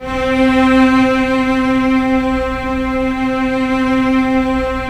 Index of /90_sSampleCDs/Roland LCDP13 String Sections/STR_Vcs I/STR_Vcs1 Sym Slo